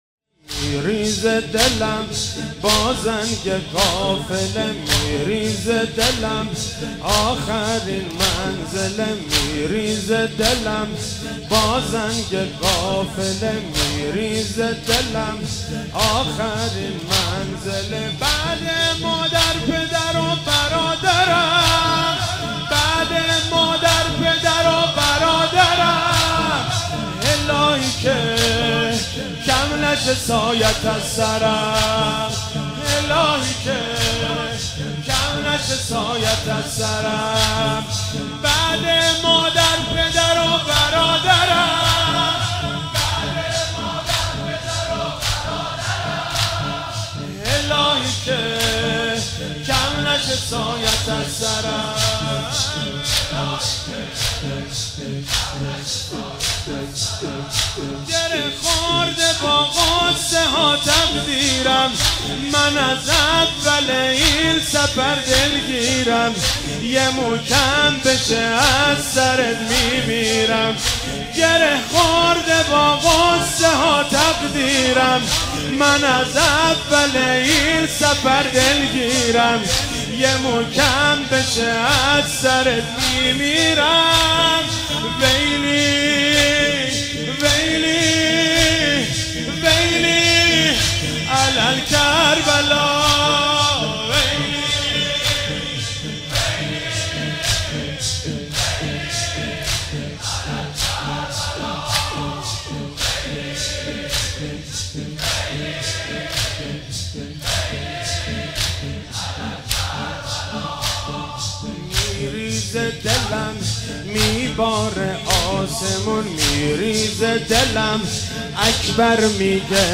زمینه ریتمی و باحال
مداحی زمینه